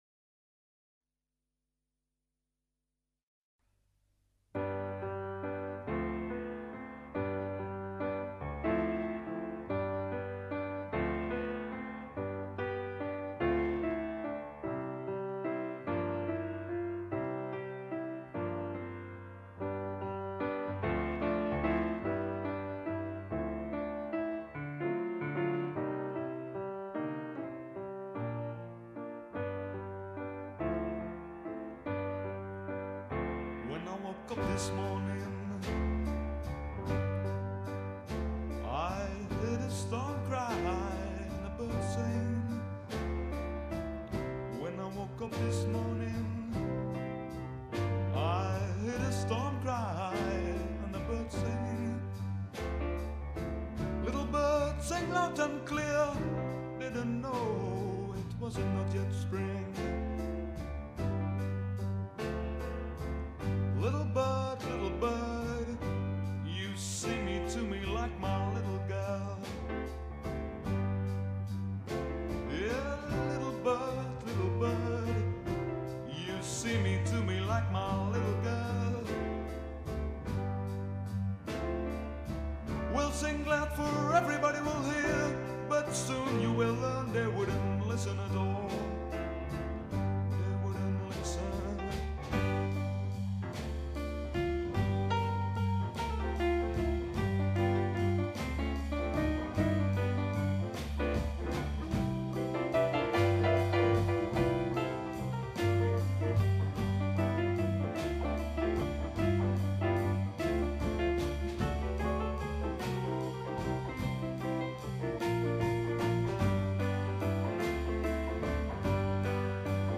Enkele studio opnames:
-RONO Studio Leeuwarden 1969